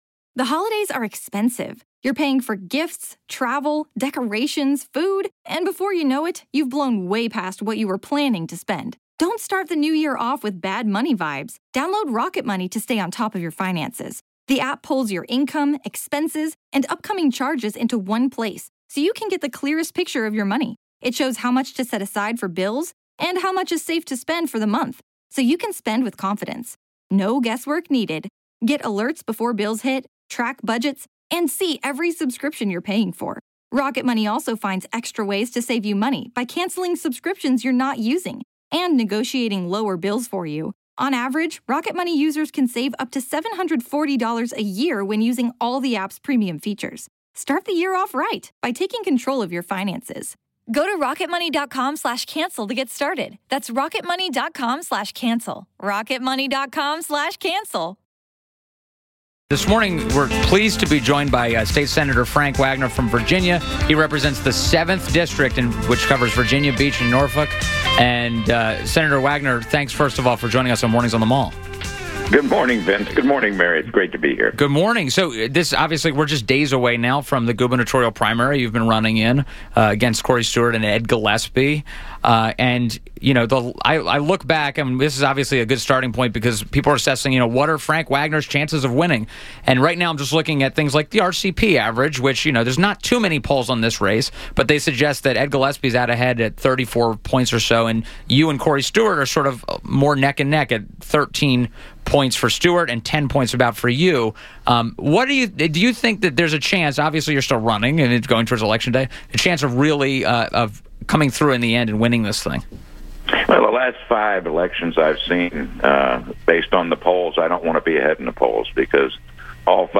FRANK WAGNER - Republican candidate for VA Governor Topic: Upcoming election• The Washington Post’s endorses Frank Wagner in Virginia’s gubernatorial primaries